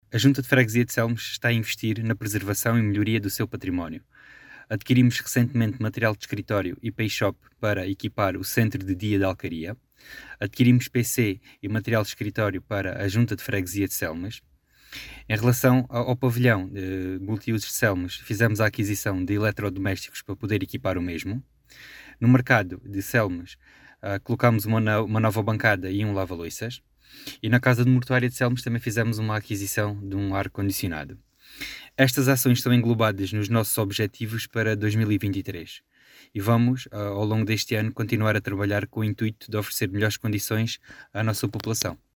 As explicações são de Sérgio Borges, presidente da junta de freguesia de Selmes, que acrescentou ainda outras intervenções levadas a cabo pela junta de freguesia, no mercado e no pavilhão da freguesia, num total de investimento de seis mil euros.